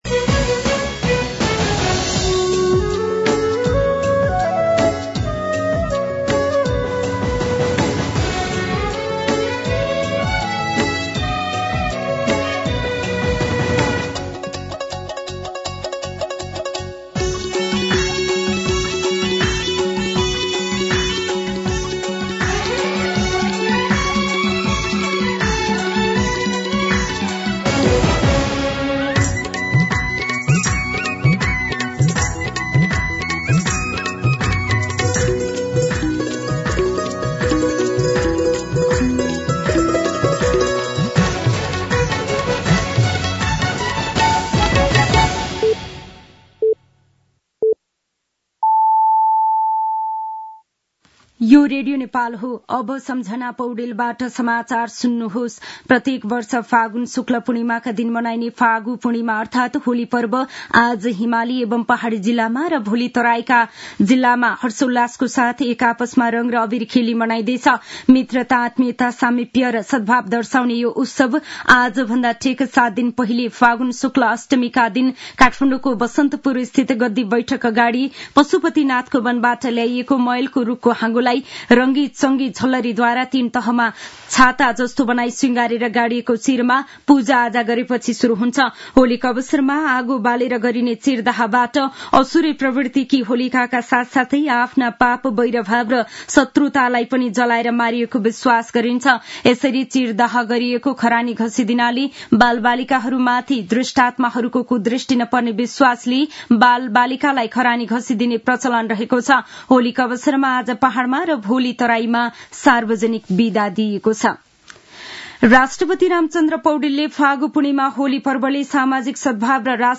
मध्यान्ह १२ बजेको नेपाली समाचार : ३० फागुन , २०८१
12-pm-news.mp3